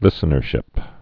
(lĭsə-nər-shĭp, lĭsnər-)